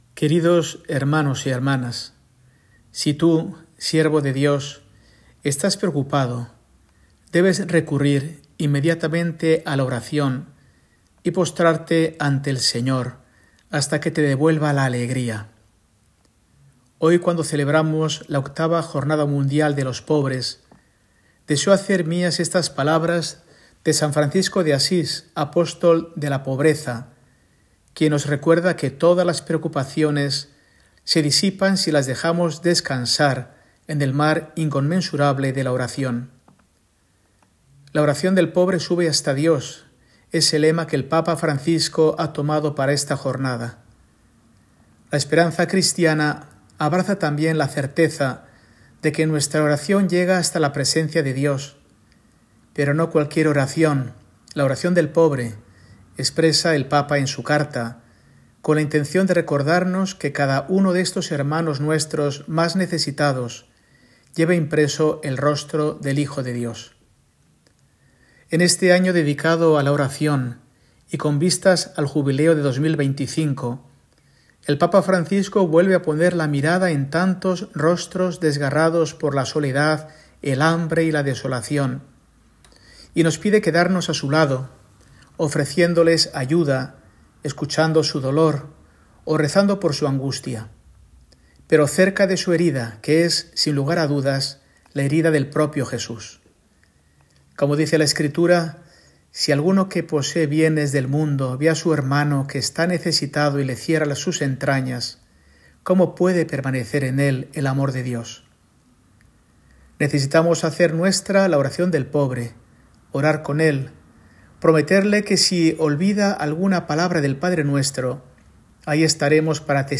Mensaje de Mons. Mario Iceta Gavicagogeascoa, arzobispo de Burgos, para el domingo, 17 de noviembre de 2024, VIII Jornada Mundial de los Pobres